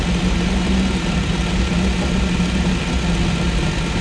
rr3-assets/files/.depot/audio/Vehicles/f1_04/f1_04_idle.wav
f1_04_idle.wav